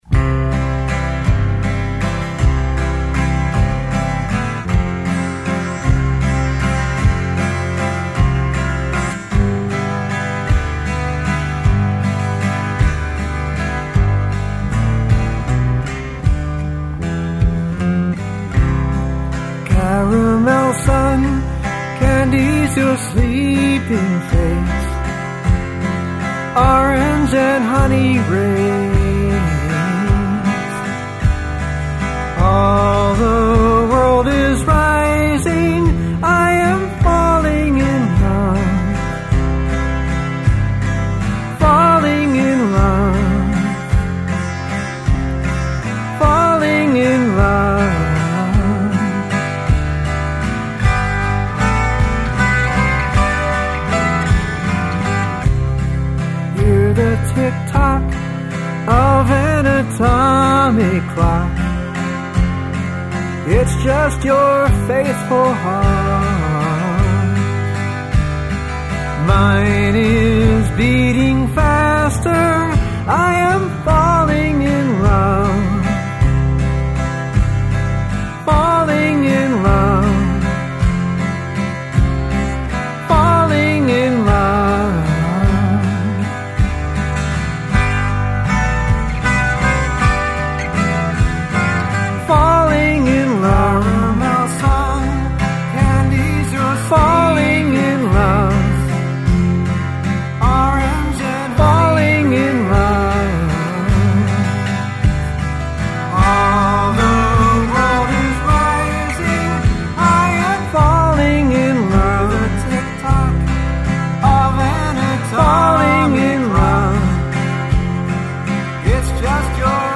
A pretty waltz, with overlapping vocals.